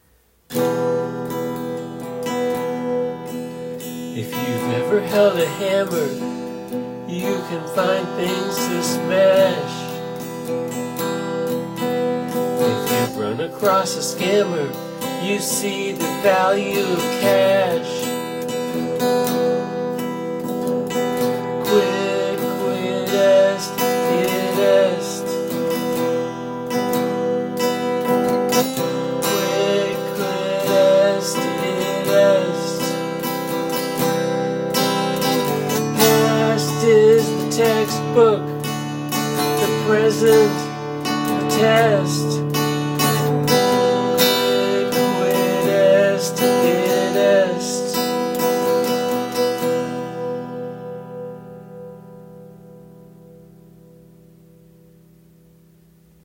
For a short quick song, there's a lot packed into those lyrics.
Ooh I like how moody this one is!
Very cool song, sets the nostalgic mood.
Beautiful singing and playing.